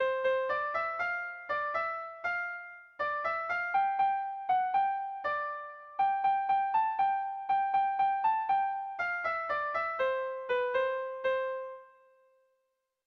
Bertso melodies - View details   To know more about this section
Kopla handia
ABD